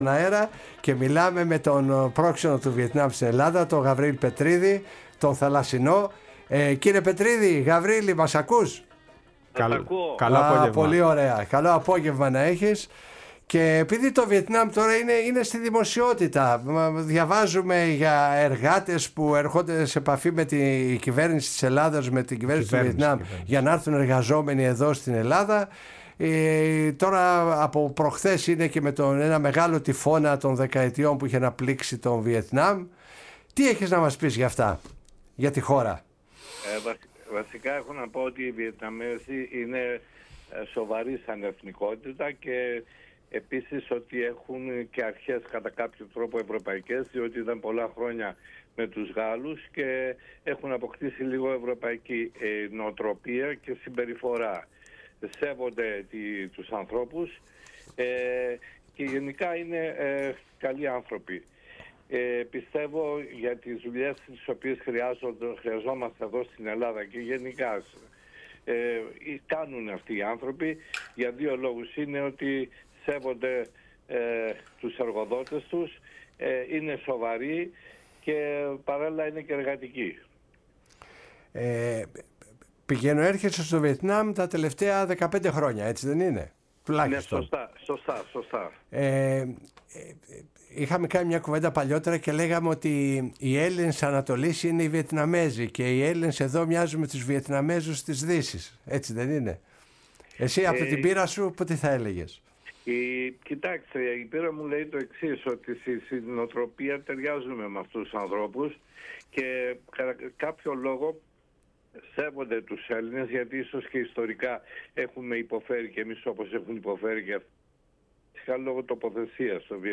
Η ΦΩΝΗ ΤΗΣ ΕΛΛΑΔΑΣ Η Παγκοσμια Φωνη μας ΣΥΝΕΝΤΕΥΞΕΙΣ Συνεντεύξεις Ανοι Βιετναμ Γαβριηλ Πετριδης ΕΛΛΑΔΑ ΝΑΥΤΙΛΙΑ τυφωνας Γιαγκι